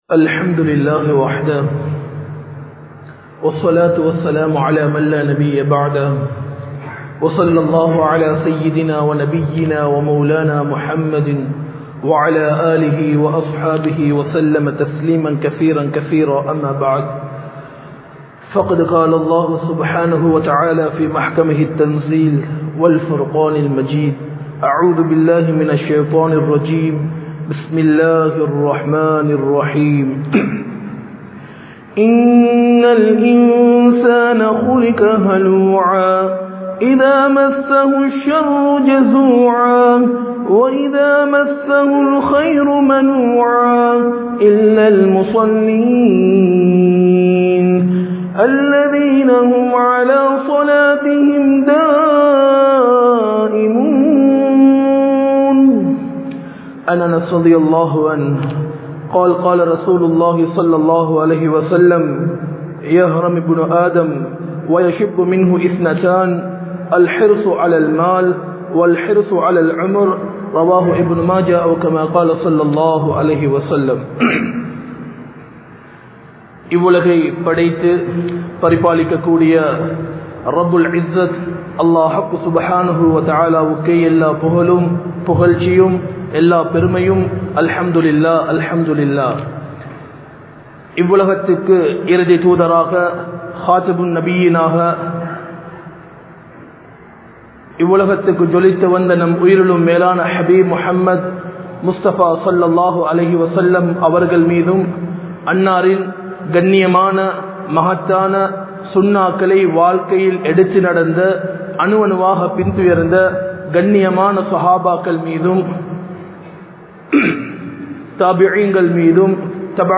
Paavangalai Vittu Vidungal (பாவங்களை விட்டுவிடுங்கள்) | Audio Bayans | All Ceylon Muslim Youth Community | Addalaichenai
Majma Ul Khairah Jumua Masjith (Nimal Road)